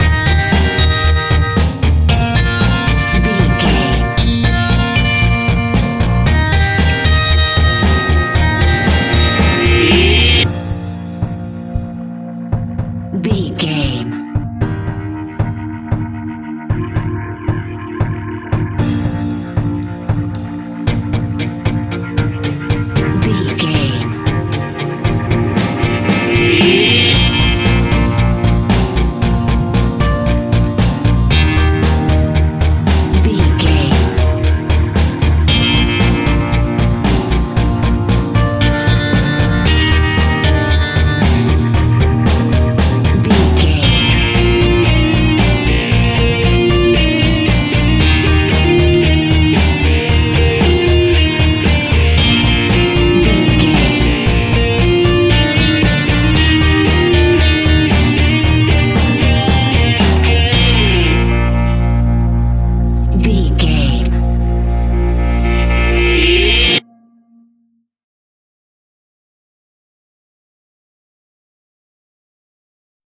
Uplifting
Ionian/Major
B♭
pop rock
indie pop
energetic
cheesy
guitars
bass
drums
piano
organ